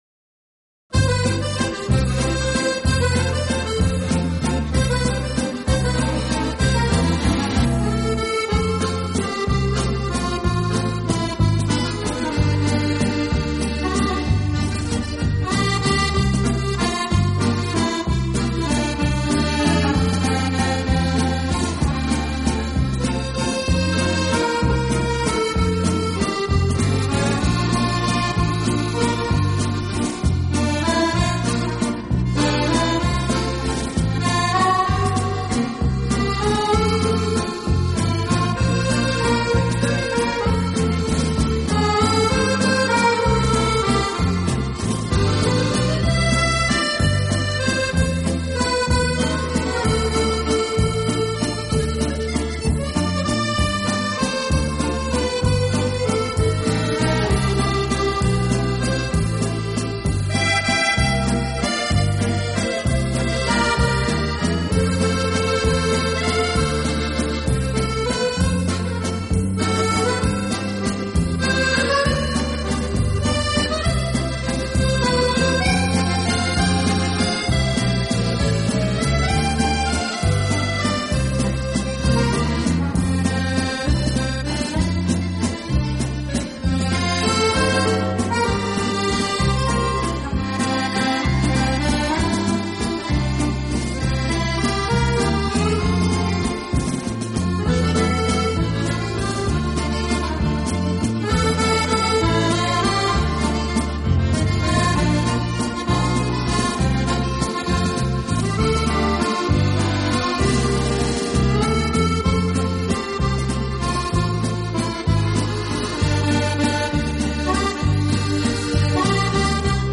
手风琴的魅力在于它的声音——美丽，轻柔，动听。